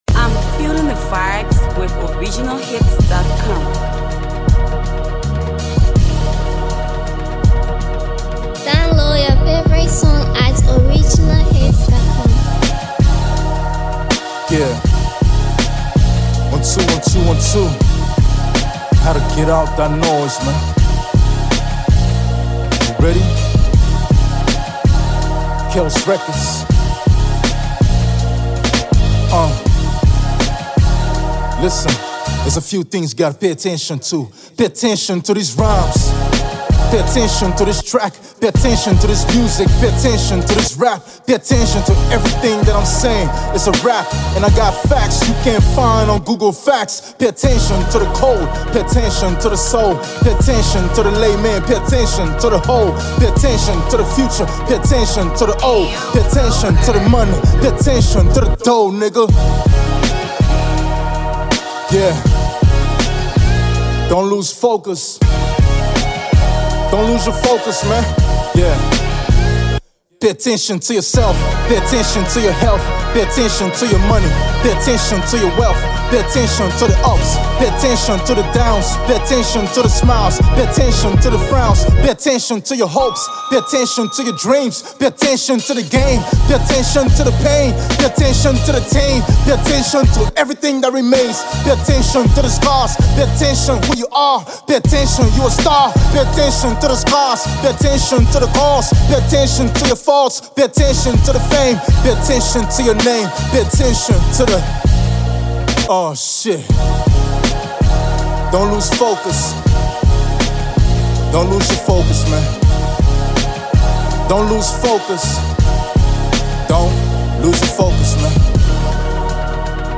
focus anthem
rapper